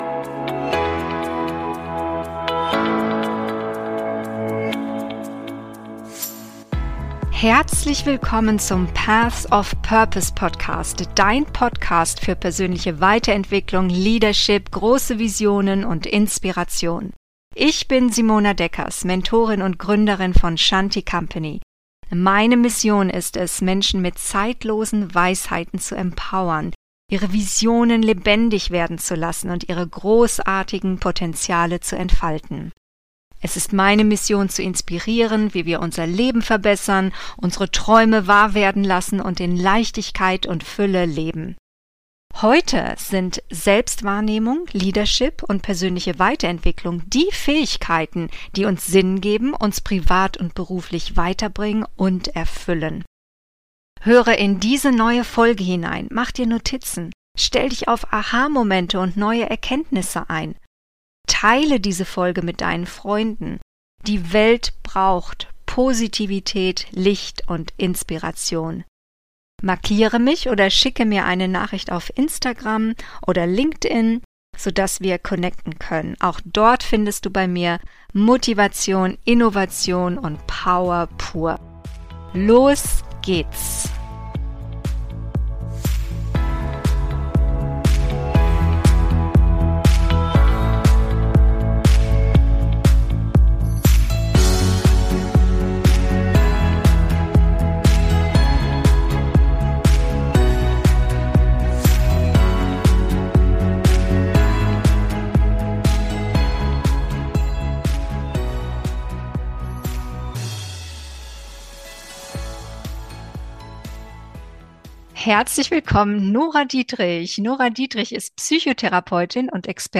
Mental Health heute– Interview